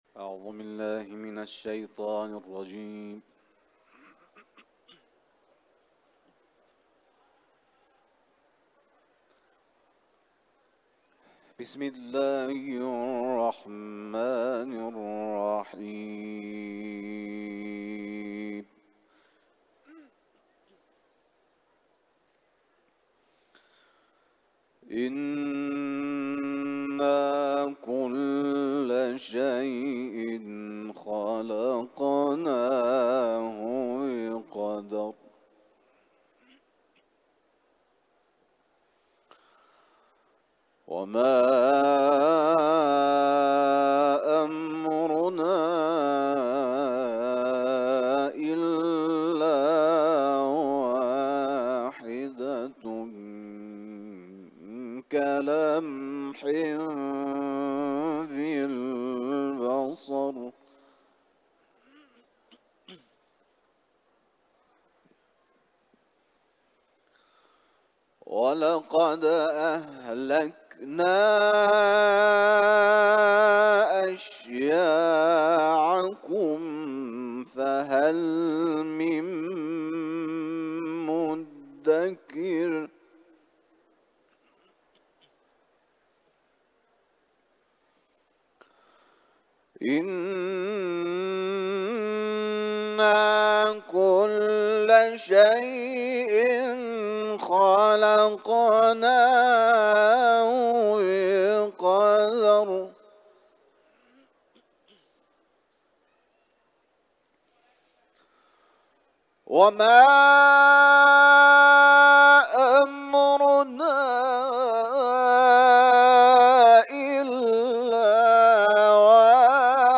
Etiketler: İranlı kâri ، Kuran tilaveti ، Kamer suresi